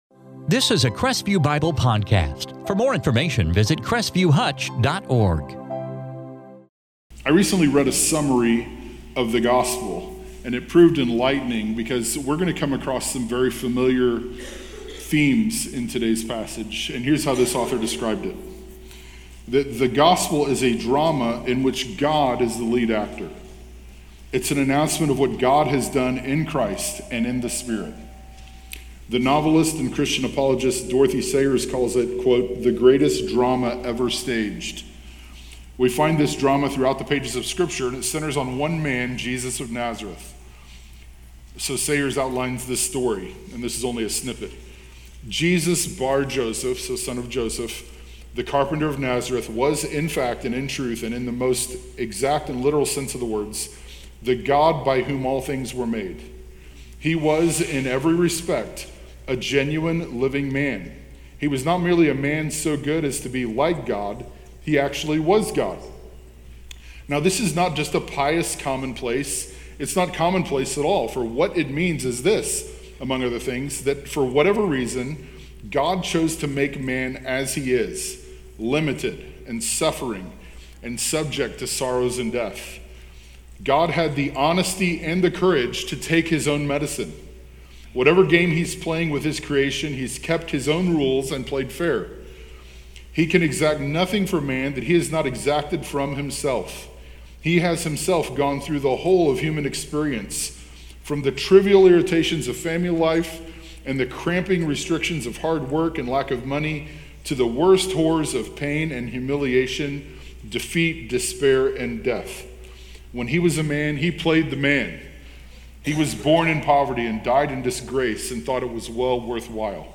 In this sermon from 2 Corinthians 5:11- 21